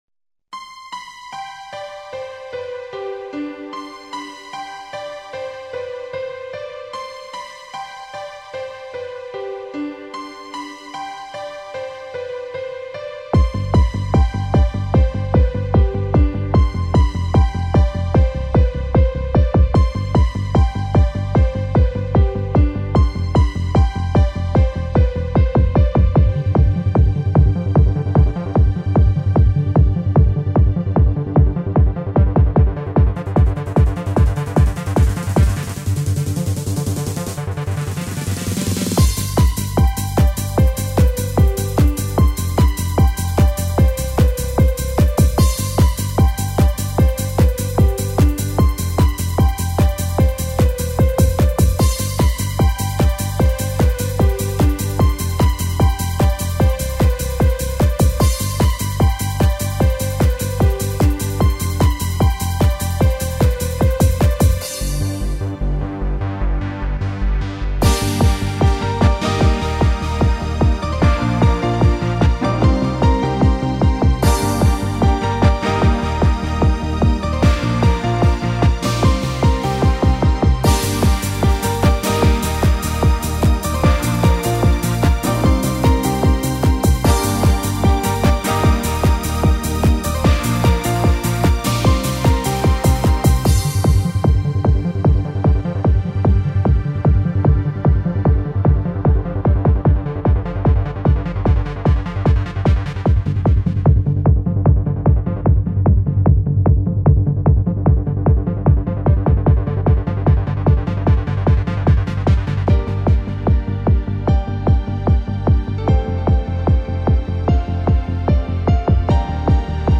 Энергия - эмоции - чувства - движение...
Жанр:Electronic